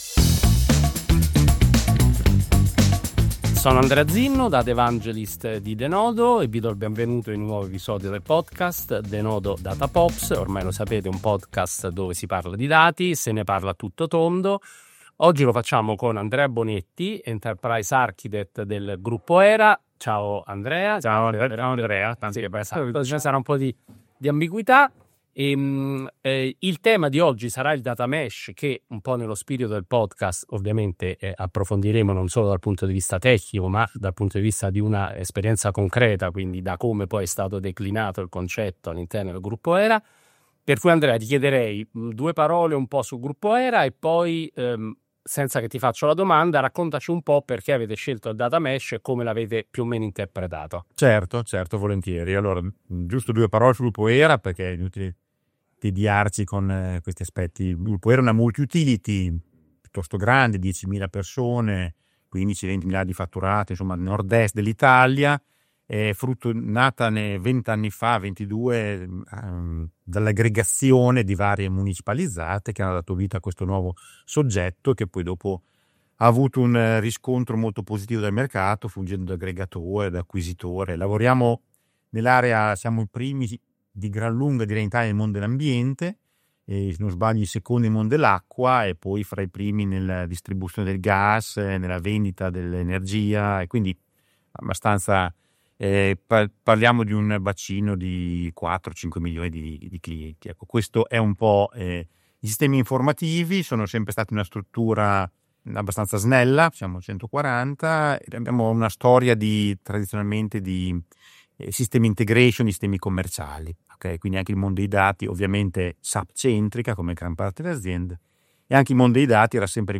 Una chiacchierata